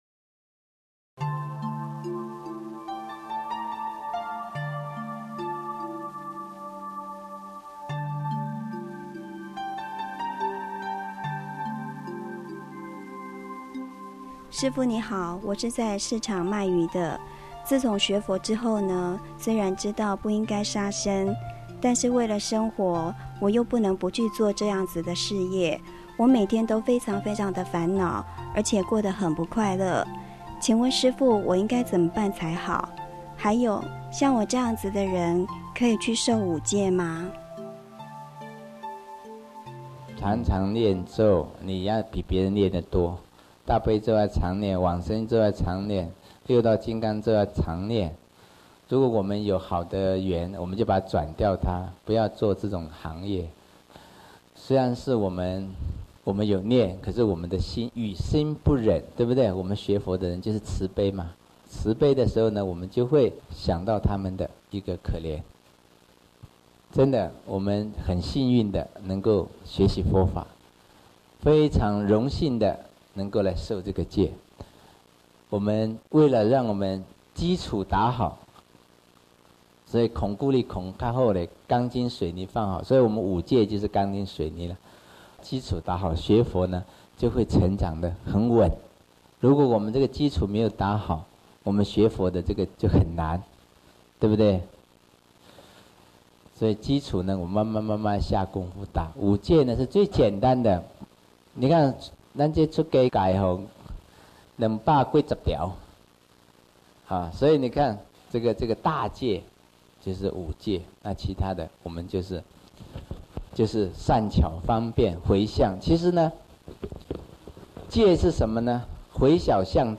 五、【觀機逗教】︰想知道師父如何在日常生活中教化徒眾嗎？廣播劇帶您重回現場一睹究竟。